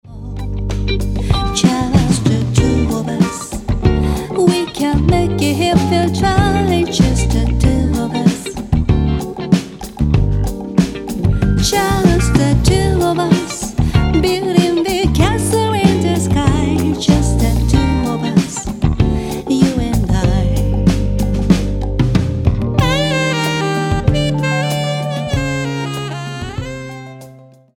70年代ソウルミュージックの名曲をカヴァーしたリスペクトアルバム